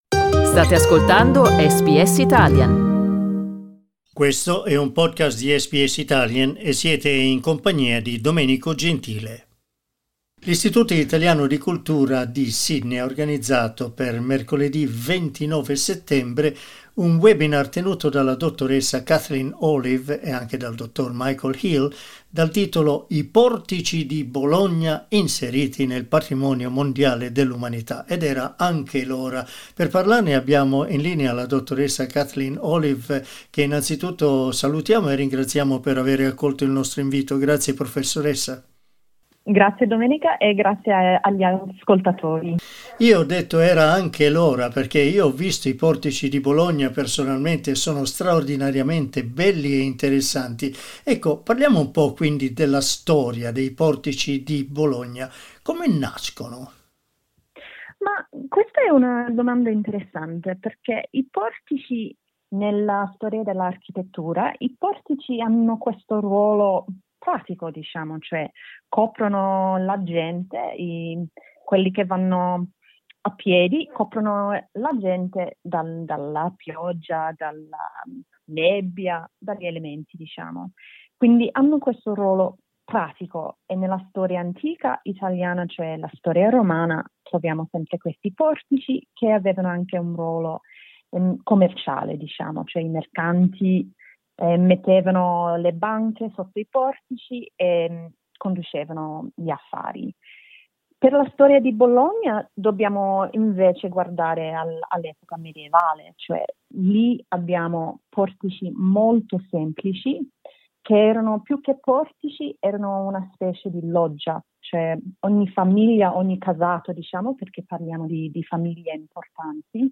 Ascolta l'intervista: LISTEN TO I portici di Bologna patrimonio artistico dell'Unesco SBS Italian 12:17 Italian Una immagine dei portici di Bologna.